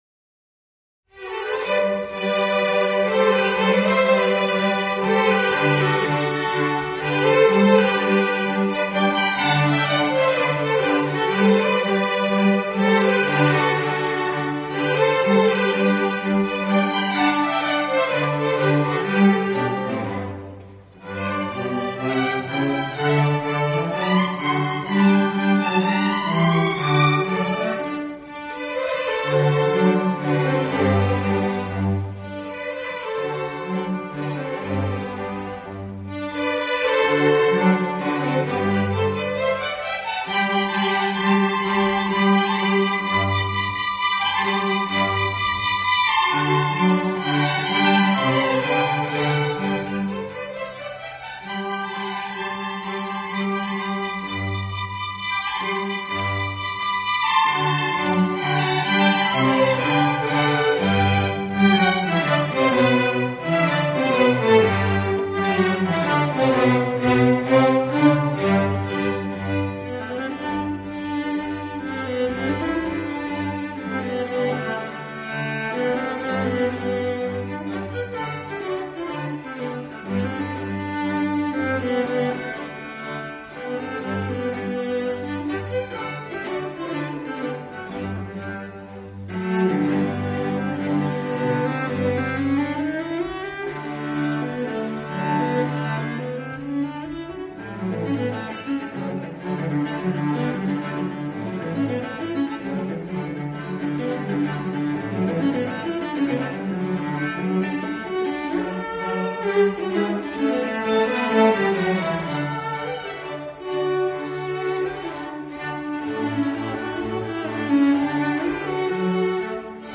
Frühjahrskonzert 2015
Konzertmitschnitt (P-Seminar)